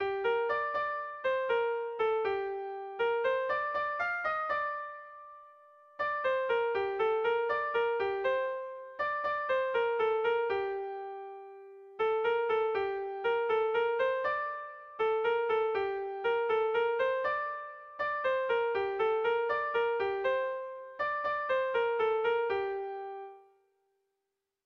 Bertso melodies - View details   To know more about this section
Erromantzea
Zortziko txikia (hg) / Lau puntuko txikia (ip)
ABDB